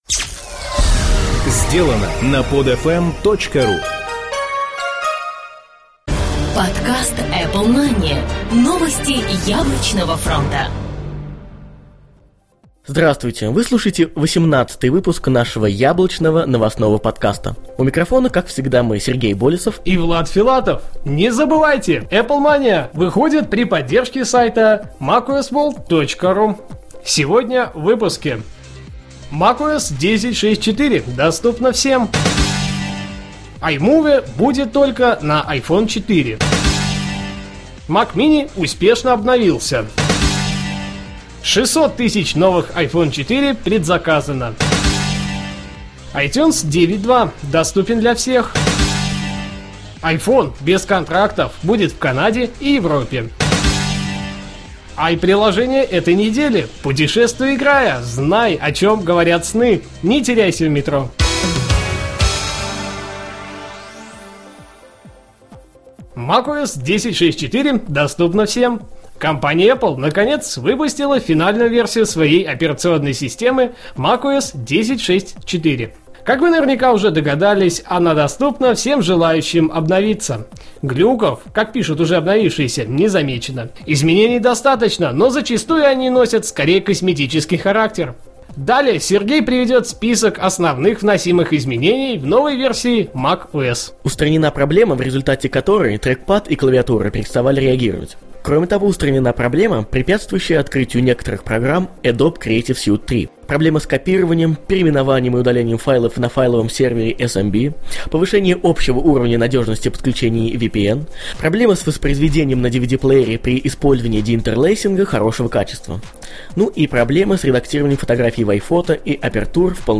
Жанр: новостной Apple-podcast